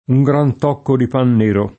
tocco [t0kko] s. m. («pezzo; cosa da poco»); pl. ‑chi — es. con acc. scr.: povera vittima, Con quel tòcco di paga! [p0vera v&ttima, koj kUel t0kko di p#ga!] (Giusti); un gran tòcco di pan nero [